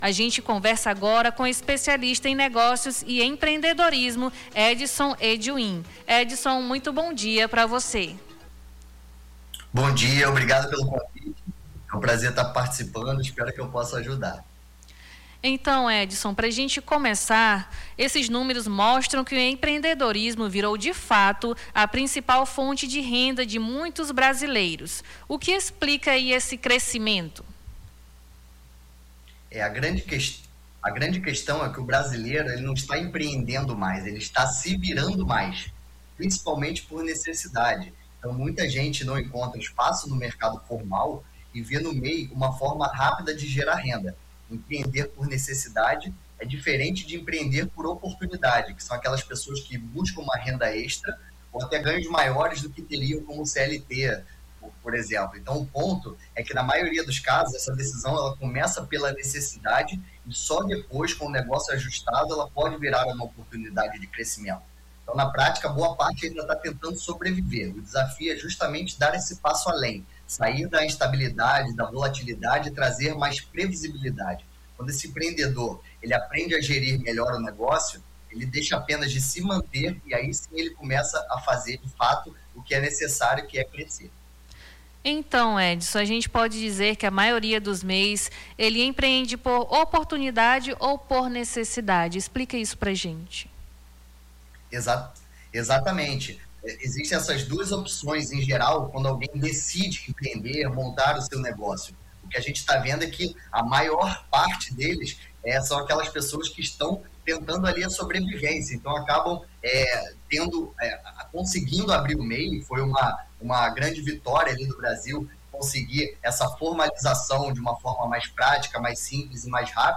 AO VIVO: Confira a Programação